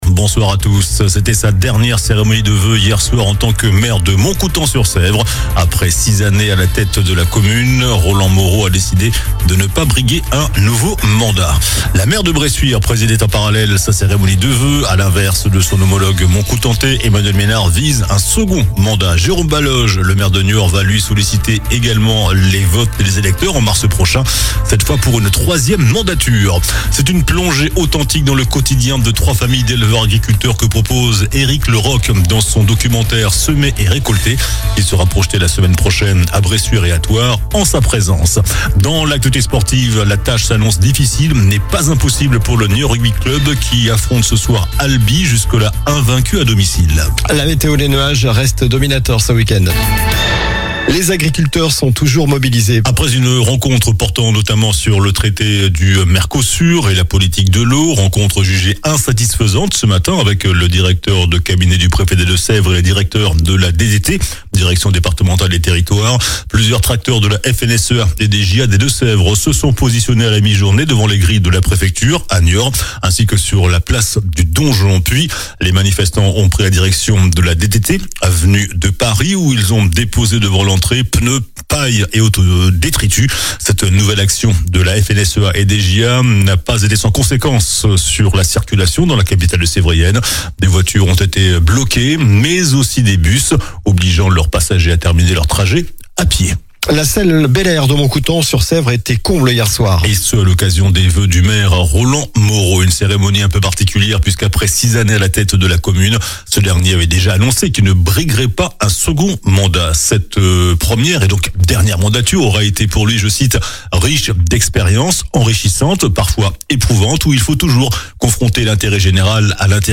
JOURNAL DU VENDREDI 16 JANVIER ( SOIR )